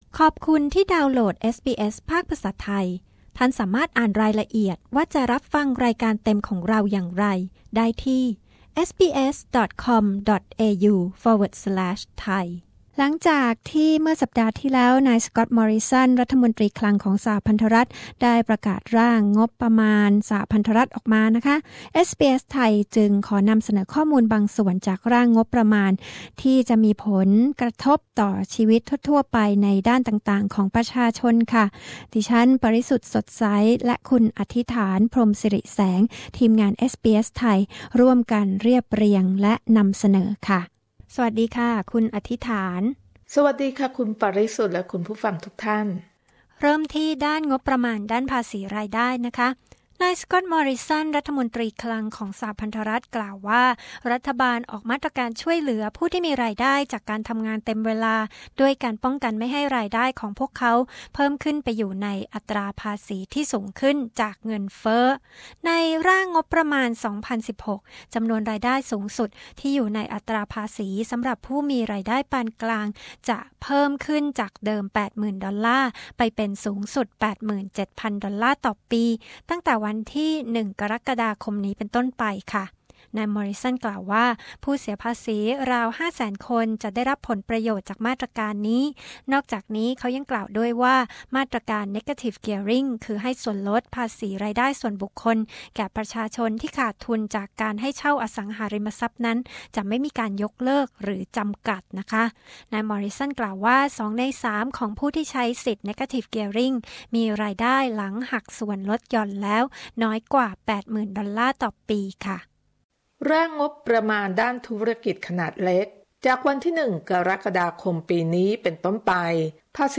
เอสบีเอส ไทย มีรายงานสรุปว่า ร่างงบประมาณของรัฐบาลสหพันธรัฐ ของปีนี้ จะส่งผลอย่างไรต่อชีวิตประจำวันของเรา ทั้งเรื่องภาษีรายได้ ภาษีธุรกิจขนาดเล็ก เงินซุปเปอร์ บริการด้านสุขภาพ บริการช่วยหางานทำ ค่าวีซ่า ค่าทำหนังสือเดินทาง และบุหรี่